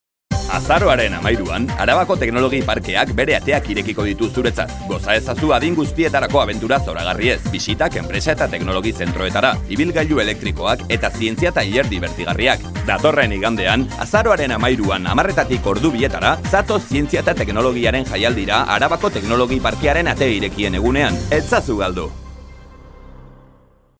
locutor vasco